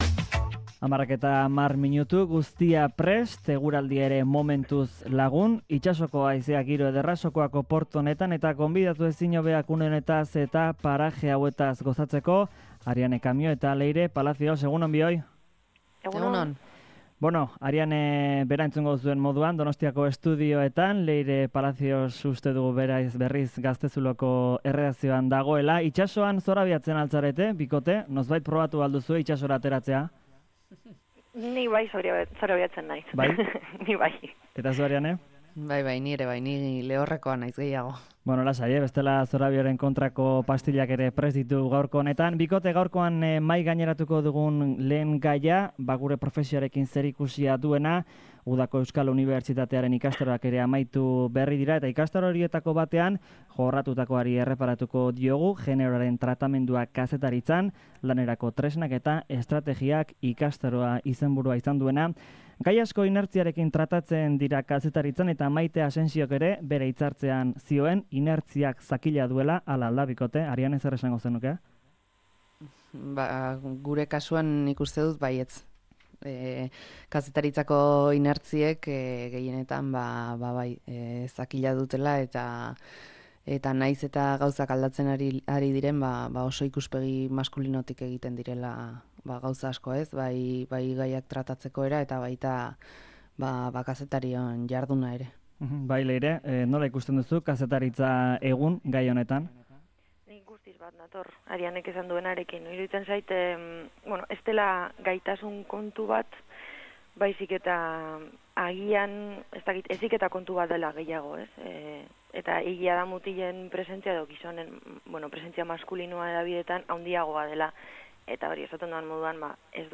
Tertulia Karelean saioan